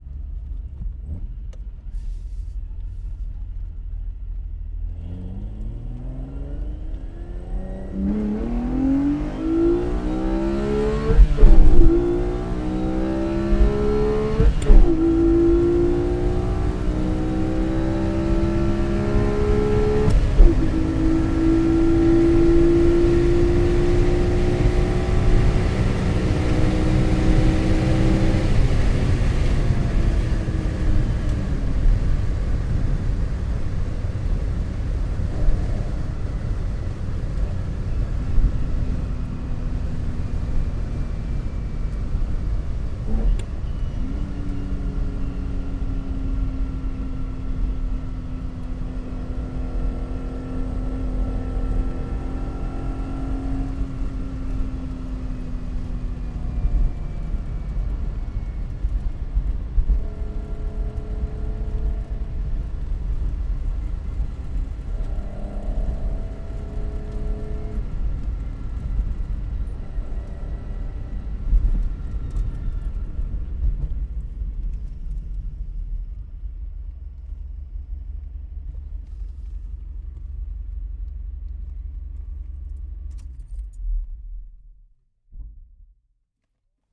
Звук мощного разгона Ferrari изнутри салона